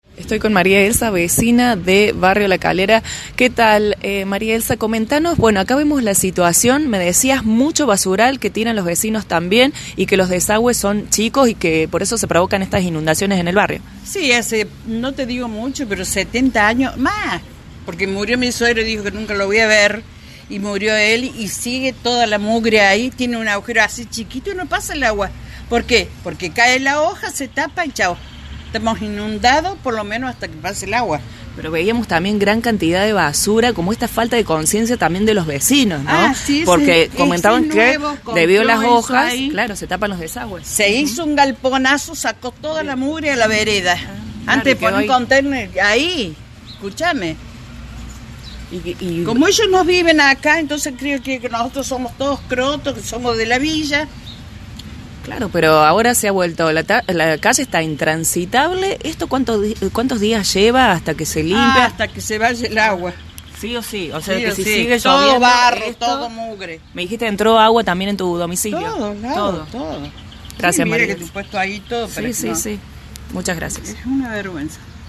TESTIMONIOS DE VECINOS